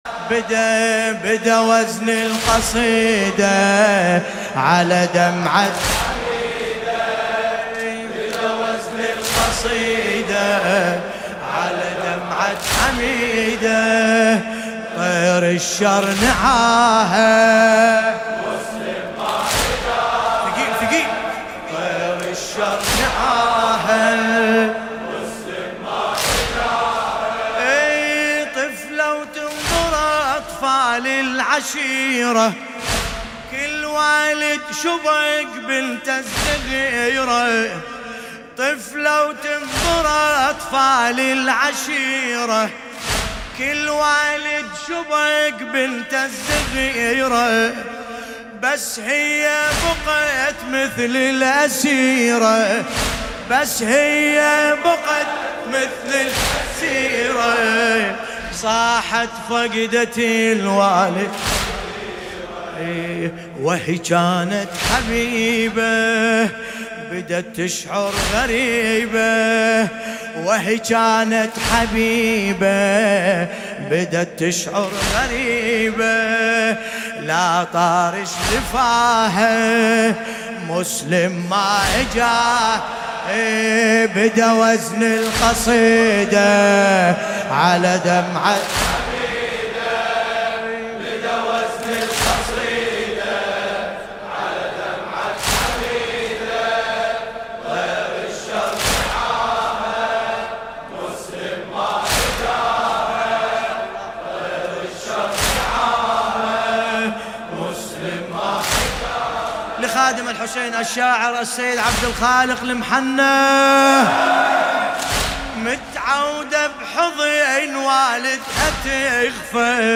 مداحی عربی